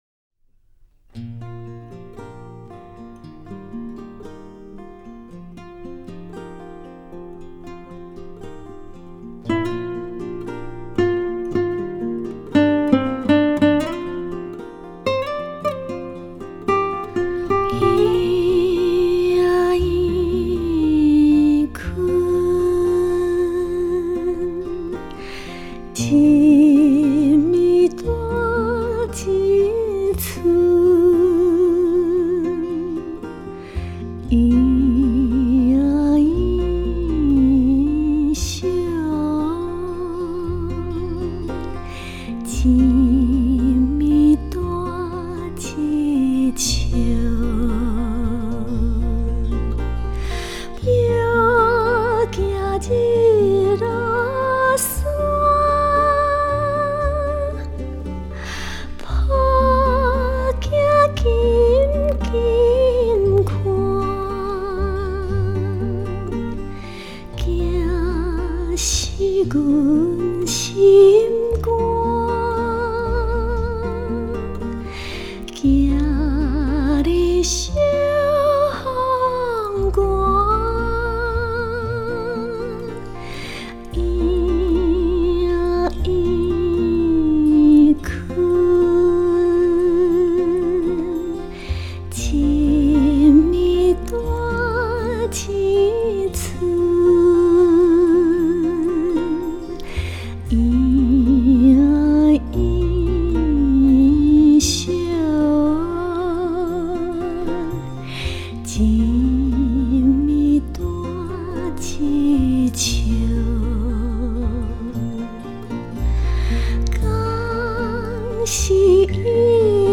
地方民歌、戏曲及其他歌类
这首以闽南语话唱出的摇篮曲，是整辑录音中唯一未有采用管弦乐伴奏的一首